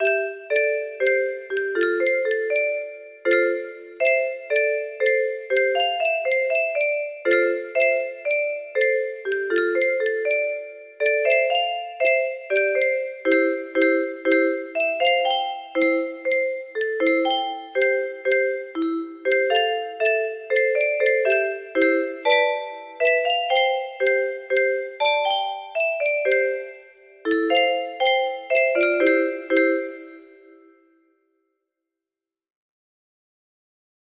222 music box melody